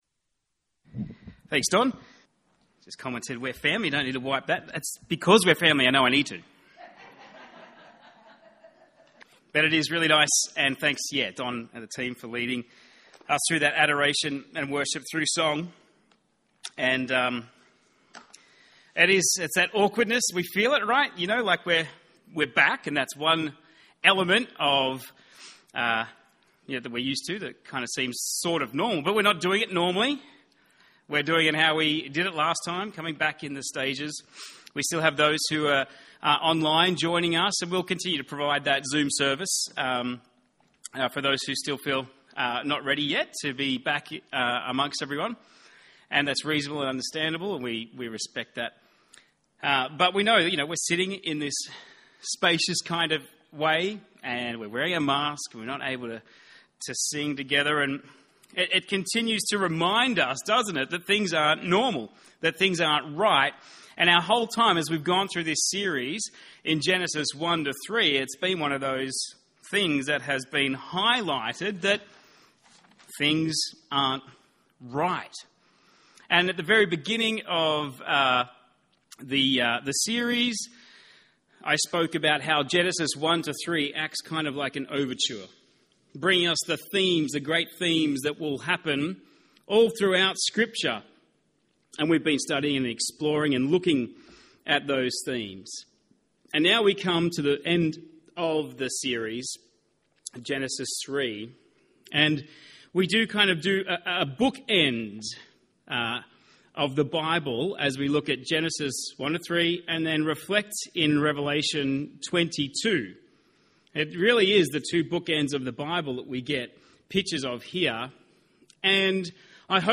by admin | Oct 24, 2021 | Genesis 1-3, Sermons 2021